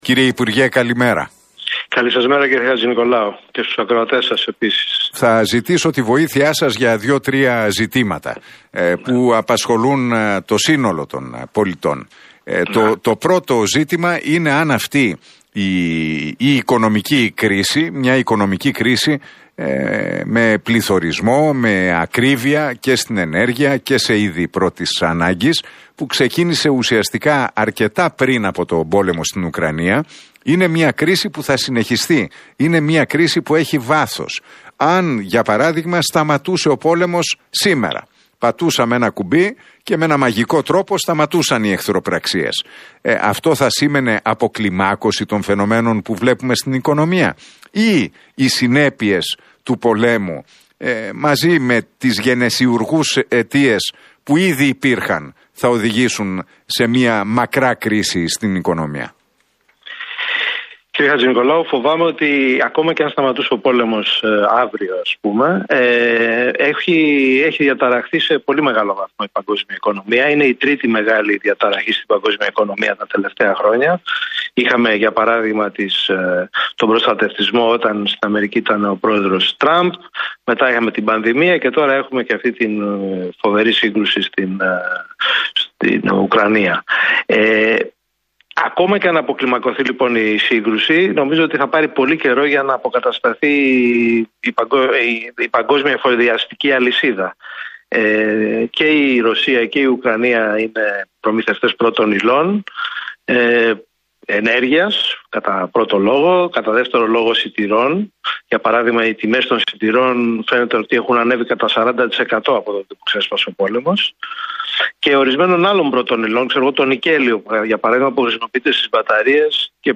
Ο πρώην υπουργός Οικονομίας και Οικονομικών και καθηγητής του Οικονομικού Πανεπιστημίου Αθηνών, Γιώργος Αλογοσκούφης, σε συνέντευξή του στον Realfm 97,8 και στην εκπομπή του Νίκου Χατζηνικολάου αναφέρθηκε στις επιπτώσεις του πολέμου ΡωσίαςΟυκρανίας στην οικονομία.